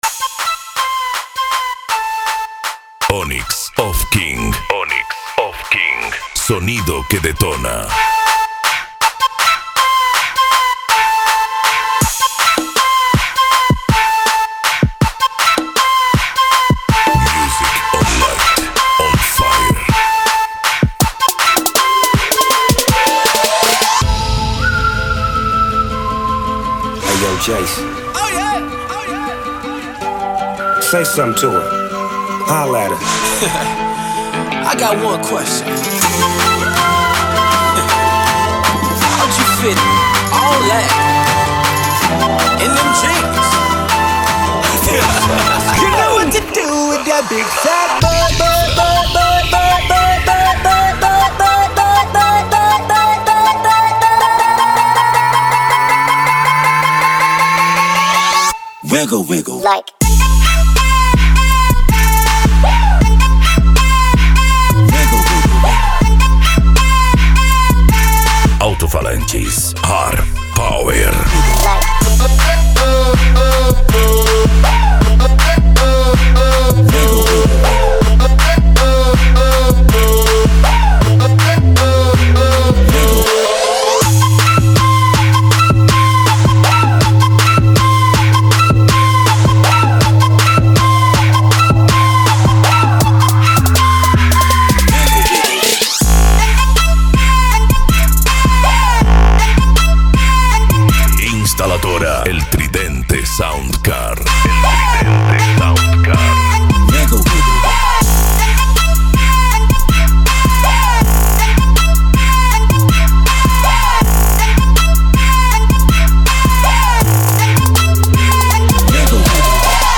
Bass
Modao
Musica Electronica
Remix